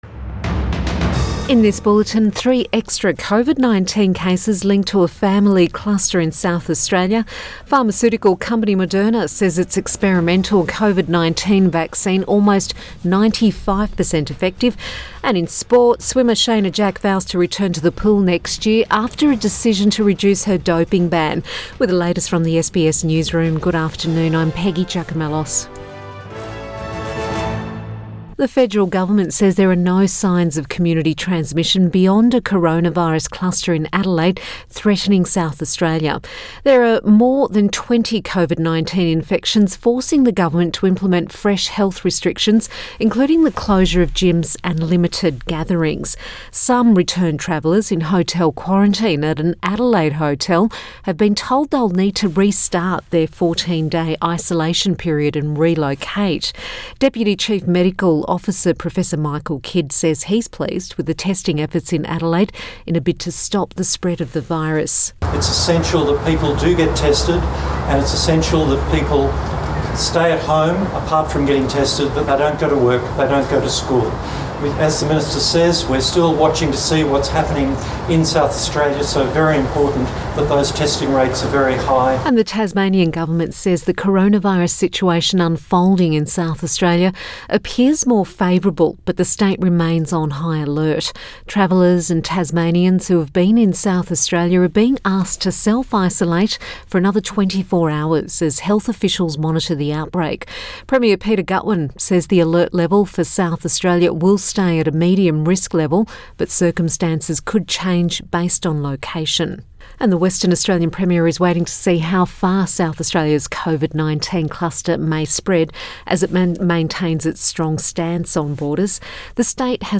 PM bulletin 17 November 2020